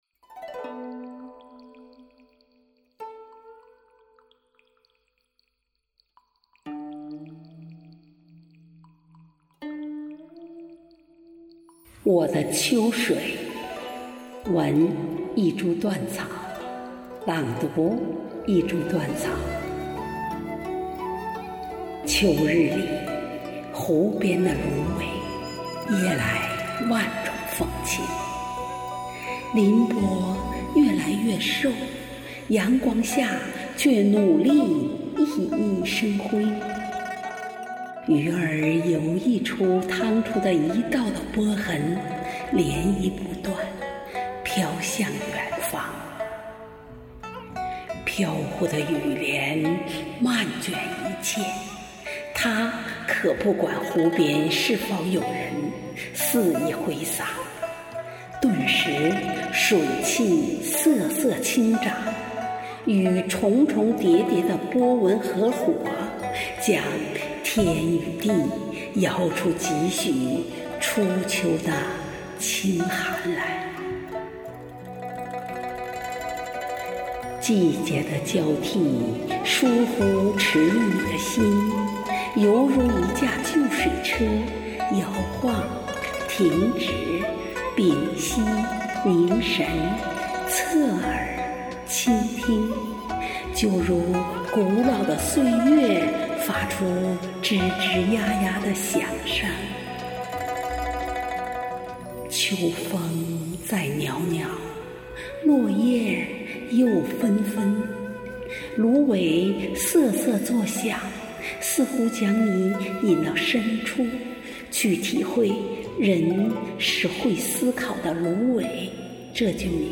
在这里听朗读效果，似乎比在手机里听更好一些。